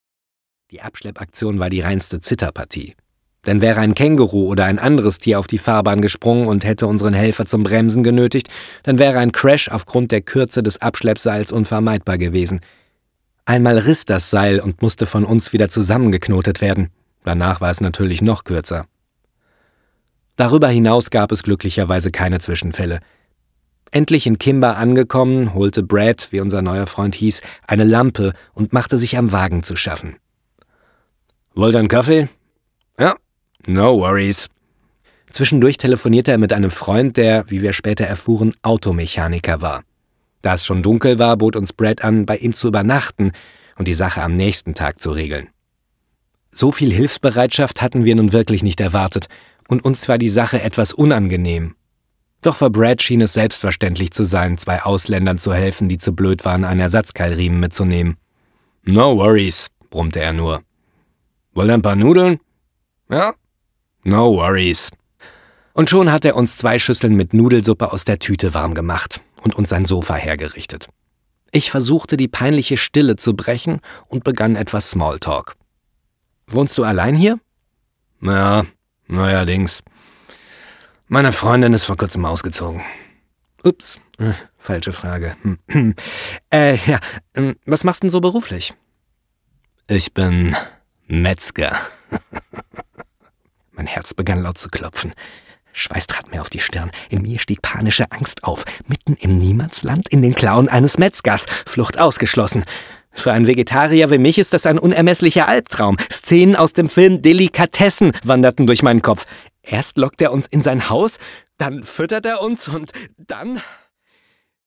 Australien-Hörbuch
Hörbuch „So fühlt sich Freiheit an – Eine Reise durch Australien“, spannende, nachdenkliche und witzige Reiseerlebnisse auf 2 CDs im liebevoll gestalteten Digipack mit 8-seitigem Booklet, handgemachter Musik sowie Originalgeräuschen aus Australien.